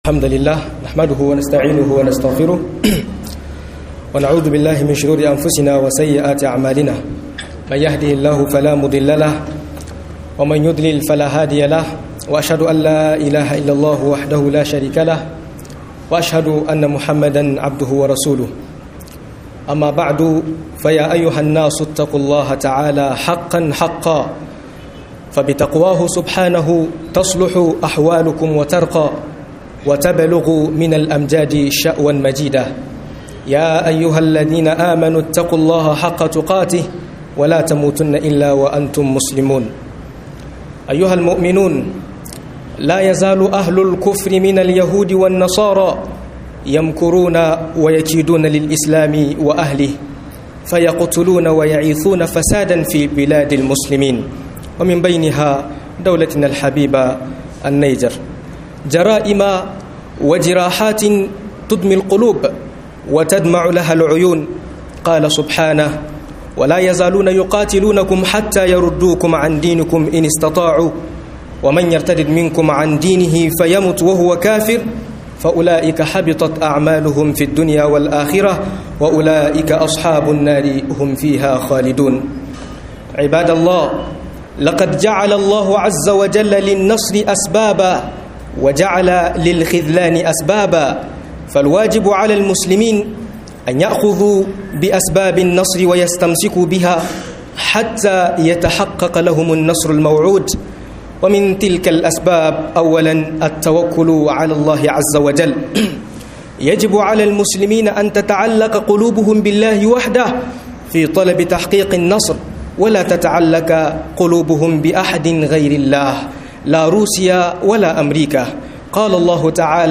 Hanyoyin cin nassara akan makiya - MUHADARA